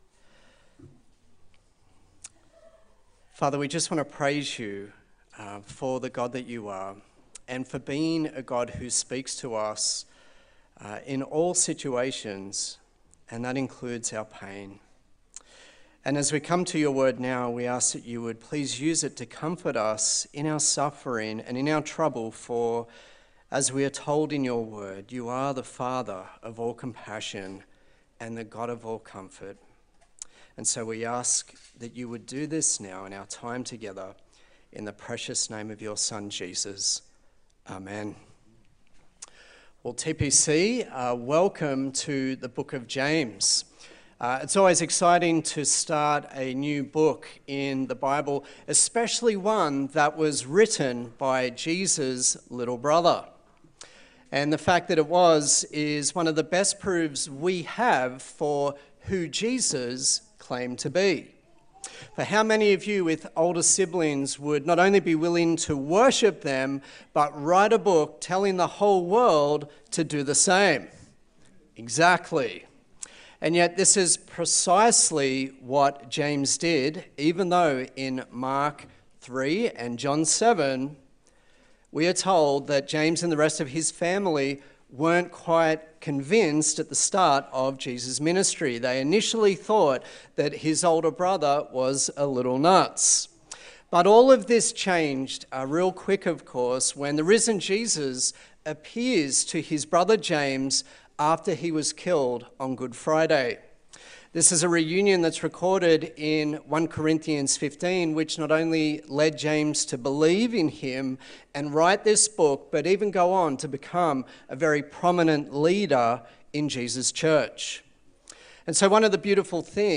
Service Type: Sunday Service A sermon